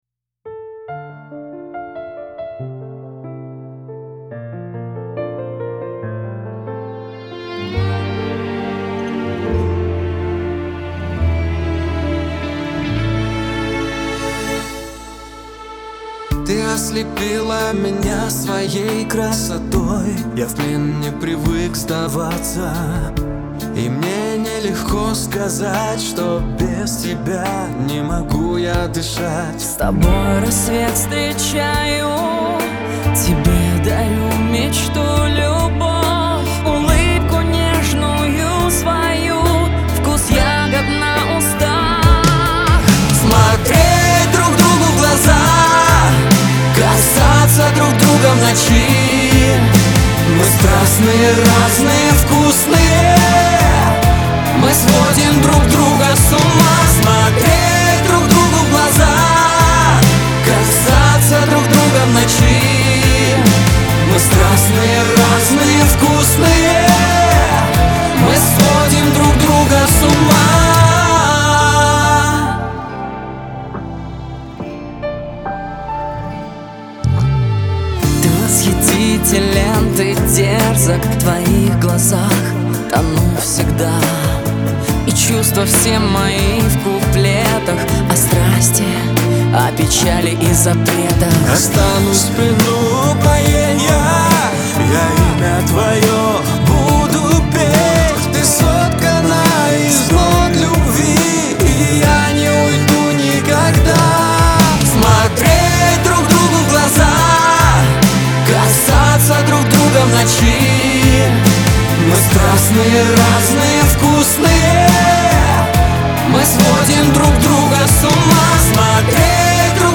pop , Лирика , дуэт
эстрада